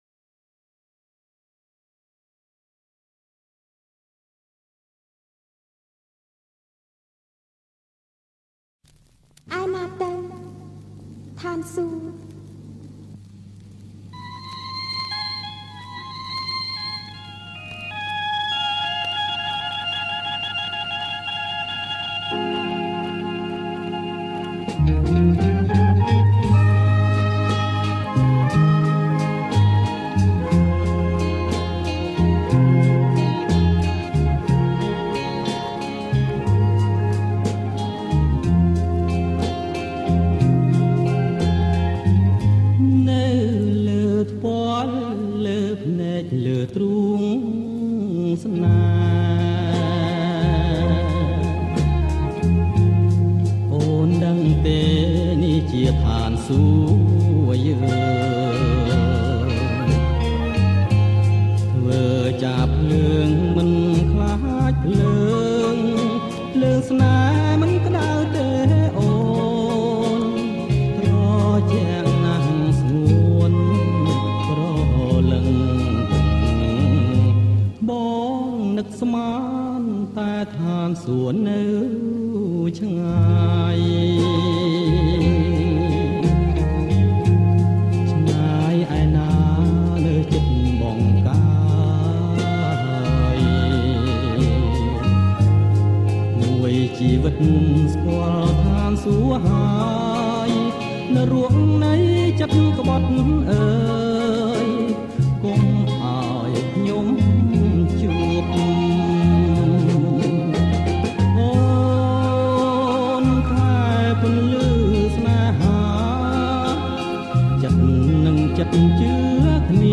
• ប្រគំជាចង្វាក់ Slow Rock
ប្រគំជាចង្វាក់ Slow Rock